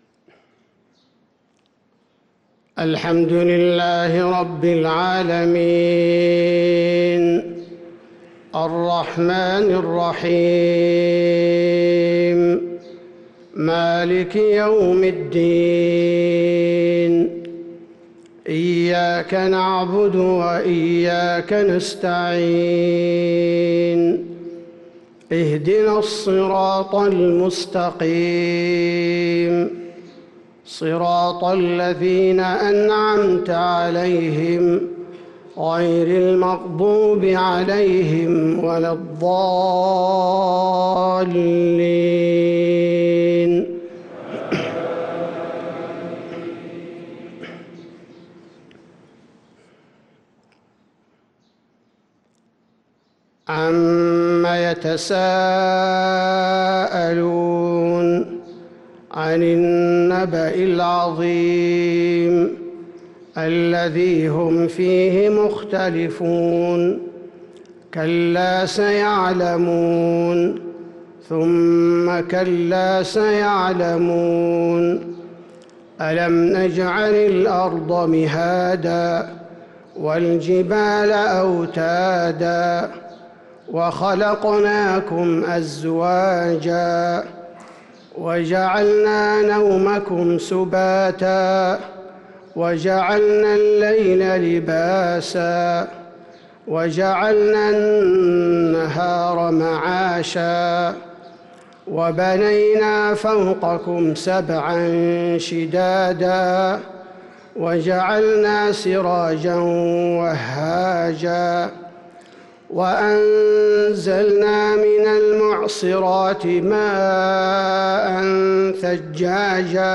صلاة الفجر للقارئ عبدالباري الثبيتي 27 جمادي الأول 1444 هـ